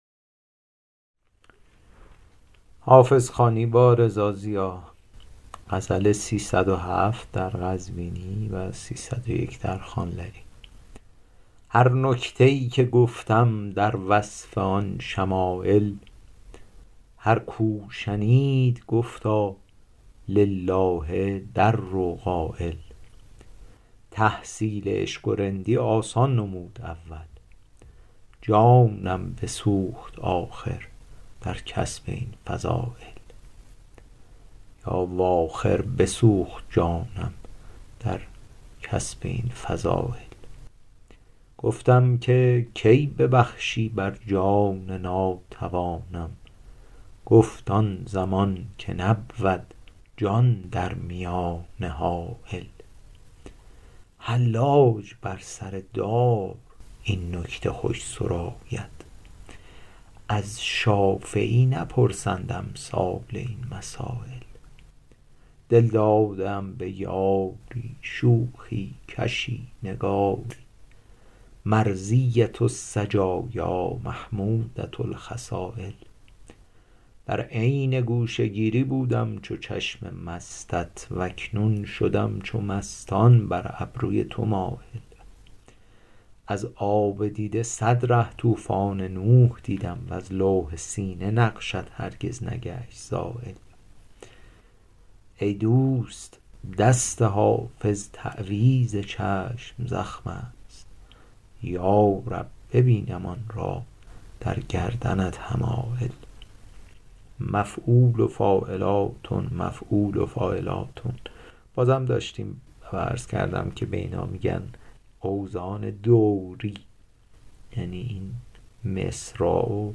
شرح صوتی